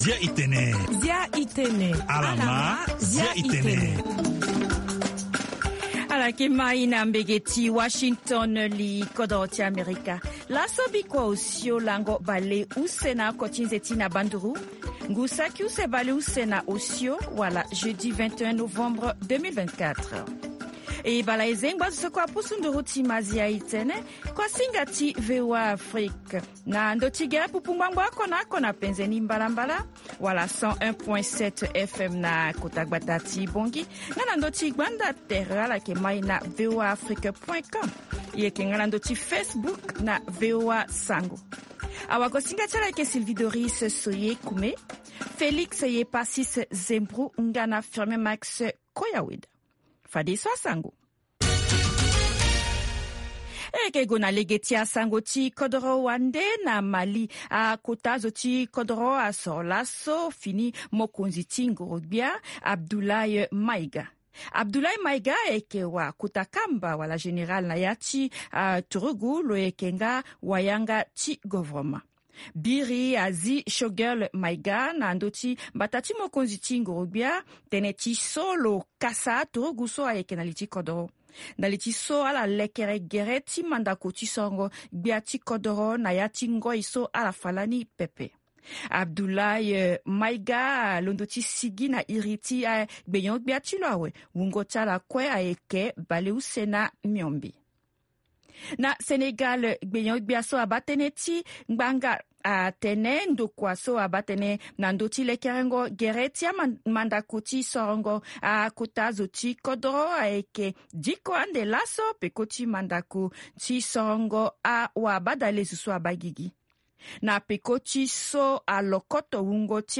Zia i Tene, est un programme en sango comportant plusieurs rubriques sur l'actualité nationale et internationale, des interviews portant sur une analyse et des réactions sur des sujets divers ainsi qu'un volet consacré aux artistes.